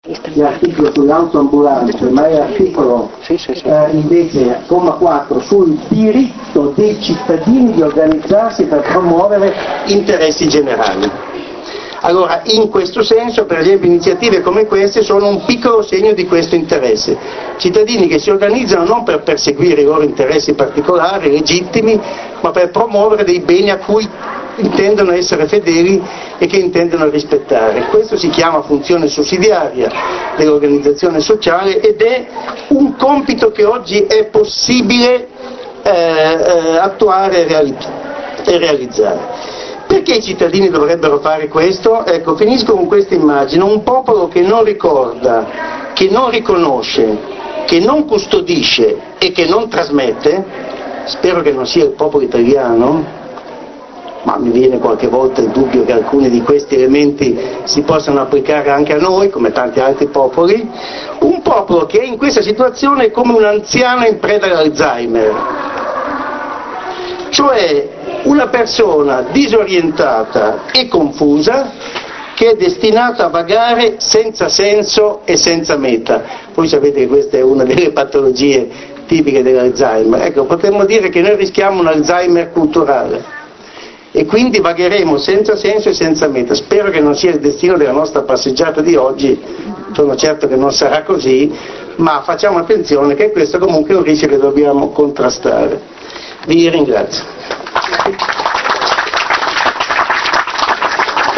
Montechiaro d’Asti - 21 giugno 2008